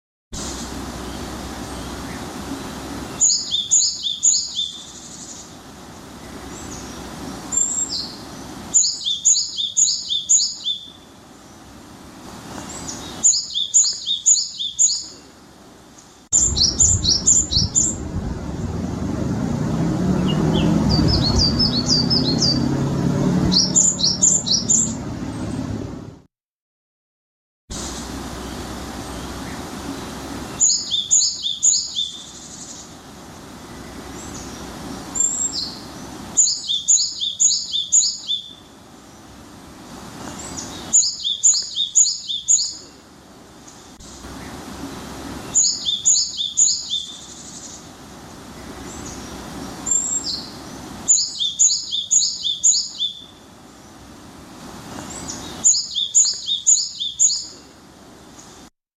シジュウカラ（さえずり）
シジュウカラ（さえずり）.mp3